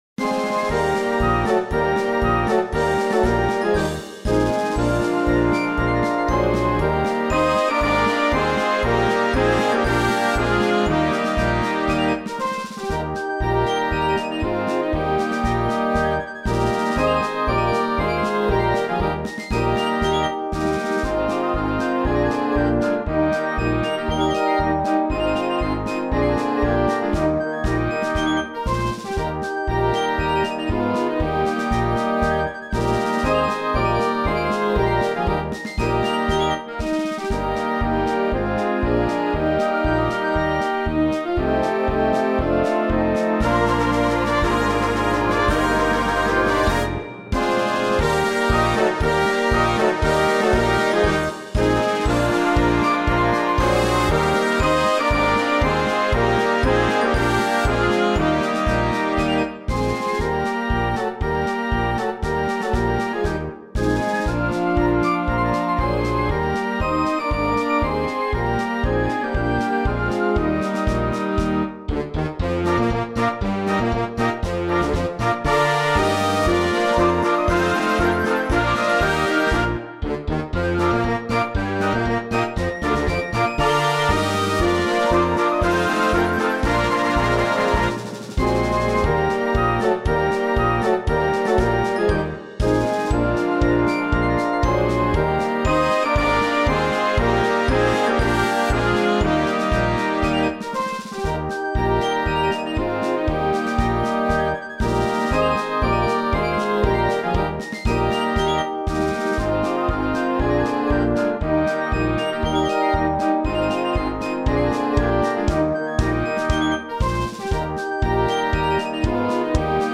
Gattung: Konzertant